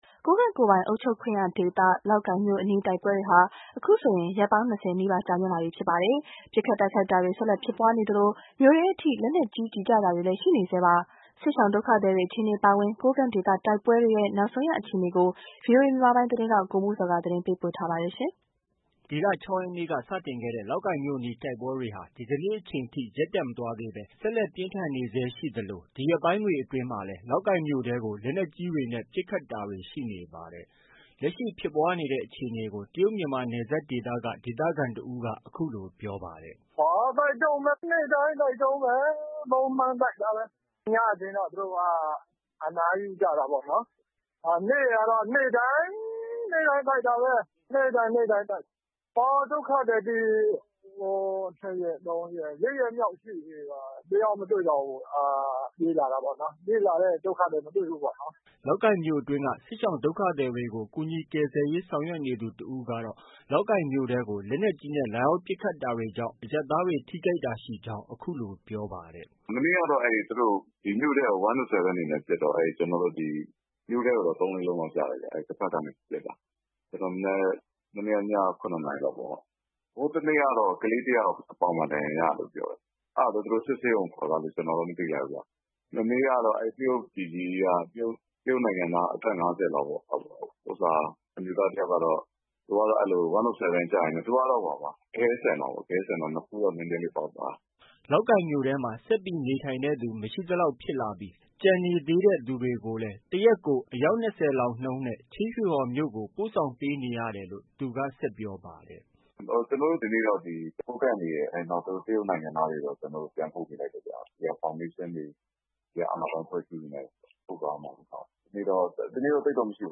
လက်ရှိ ဖြစ်ပွားနေတဲ့အခြေအနေကို တရုတ်မြန်မာနယ်စပ်ဒေသက ဒေသခံတဦးက အခုလို ပြောပါတယ်။